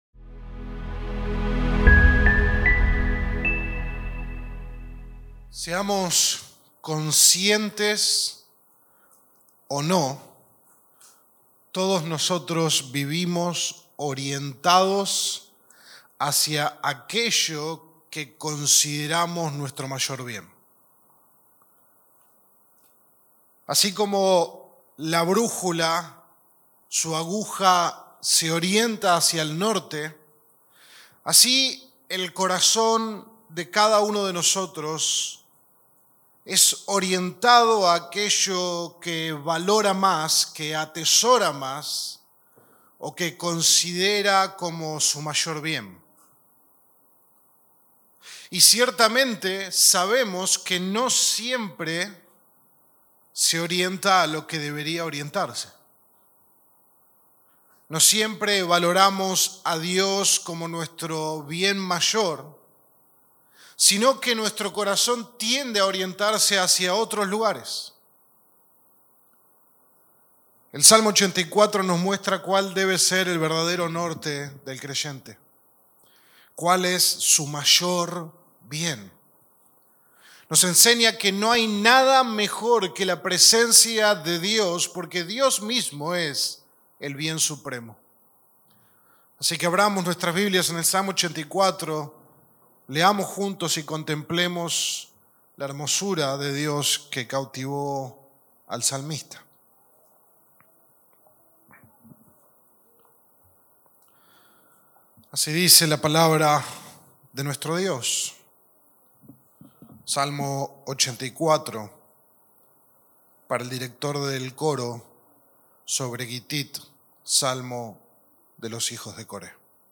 Sermón 7 de 8 en Delante de Dios